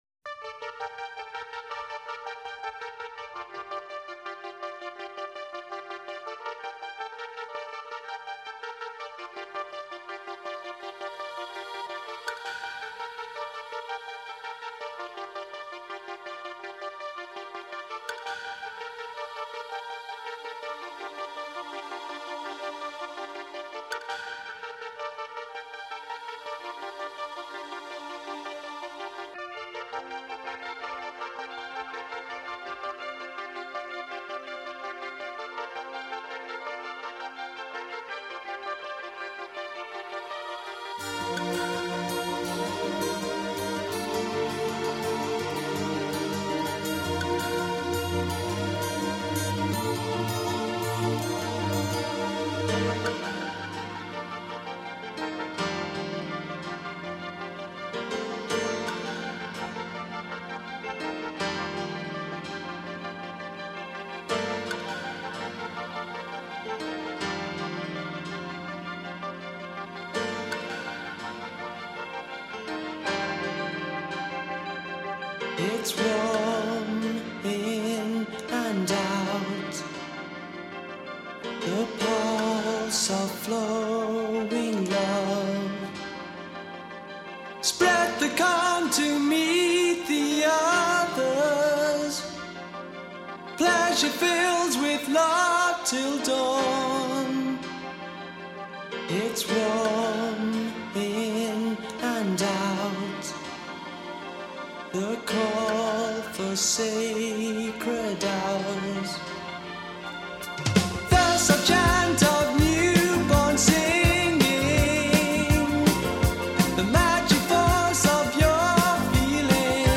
Género: Pop.